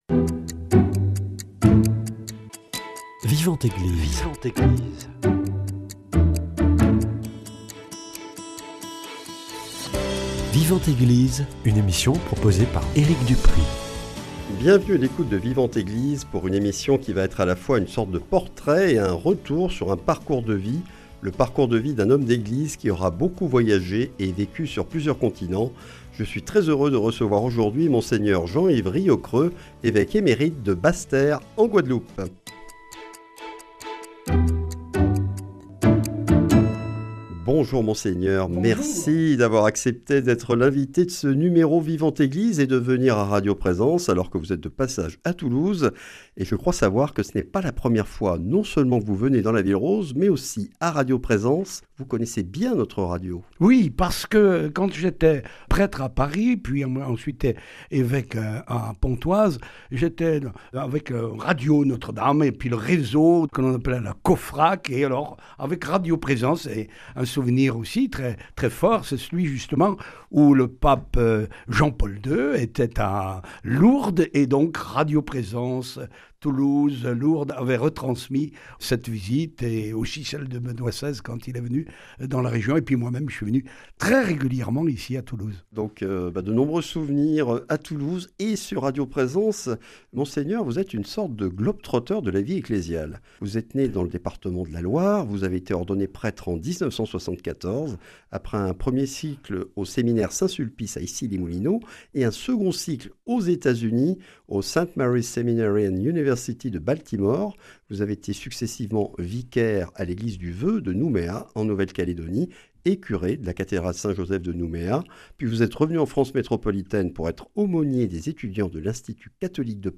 Monseigneur Jean-Yves Riocreux, évêque émérite de Basse-Terre en Guadeloupe, était de passage à Toulouse la semaine dernière après avoir participé à l’assemblée plénière de la Conférence des évêques de France à Lourdes. L’occasion d’une rencontre à radio Présence et d’un entretien avec ce véritable globe-trotteur de la vie ecclésiale, ordonné prêtre en 1974 à Nouméa, au début d’un parcours qui le mènera en Nouvelle-Calédonie, aux États-Unis, à Paris, à Pontoise et aux Antilles.